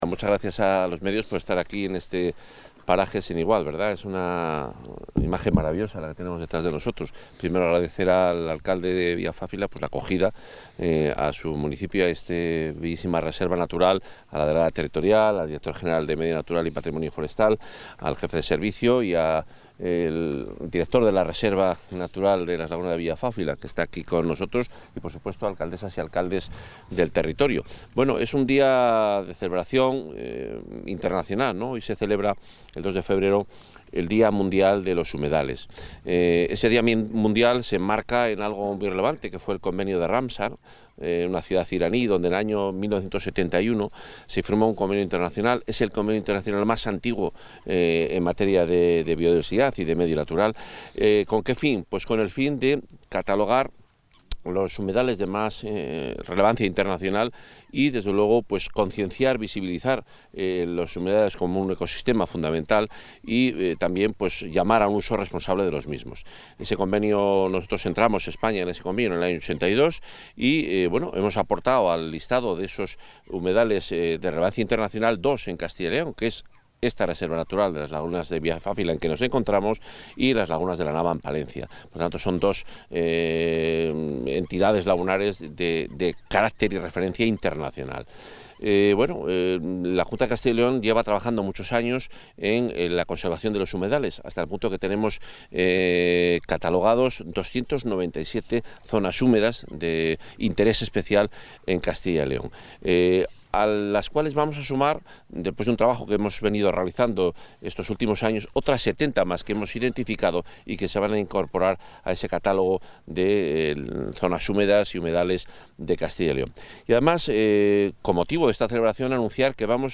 Intervención consejero Medio Ambiente, Vivienda y O. del Territorio.